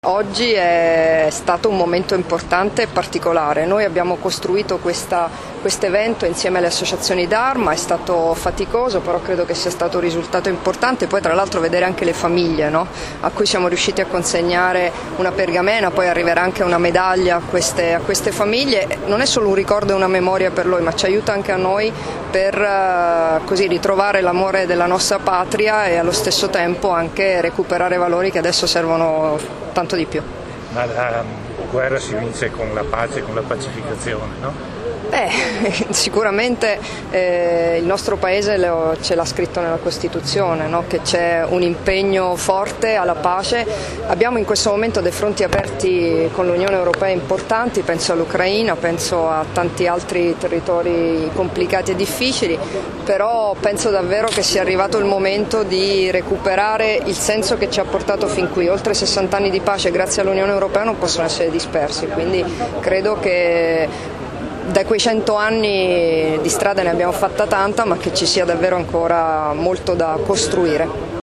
Dichiarazioni di Debora Serracchiani (Formato MP3) [1140KB]
rilasciate a margine della cerimonia di "Lettura dell'Albo d'Oro 2014-2018" in ricordo dei Caduti della Grande Guerra, al Tempio Ossario di Udine il 24 maggio 2014